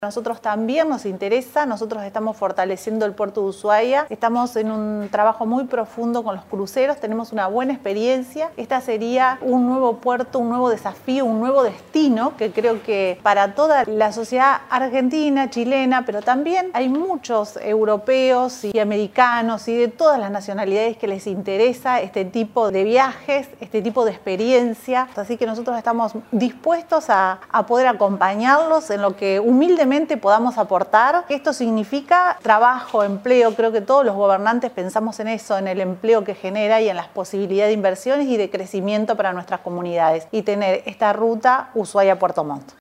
Rosana Bertone, Gobernadora de la provincia de Tierra del Fuego, dijo sentirse agradecida por la visita de trabajo de la autoridad puertomontina, y valoró la importancia y la sintonía de la iniciativa de alianza turística entre Puerto Montt y Ushuaia, agregando que el proyecto puertomontino sería un nuevo puerto, un nuevo destino y desafío para ambas sociedades, y la vez para muchos europeos y visitantes de todas las nacionalidades, que les interesa este tipo de viajes.